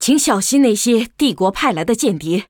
文件 文件历史 文件用途 全域文件用途 Lobato_tk_02.ogg （Ogg Vorbis声音文件，长度2.5秒，106 kbps，文件大小：32 KB） 文件说明 源地址:游戏语音 文件历史 点击某个日期/时间查看对应时刻的文件。 日期/时间 缩略图 大小 用户 备注 当前 2018年11月17日 (六) 03:35 2.5秒 （32 KB） 地下城与勇士  （ 留言 | 贡献 ） 分类:洛巴赫 分类:地下城与勇士 源地址:游戏语音 您不可以覆盖此文件。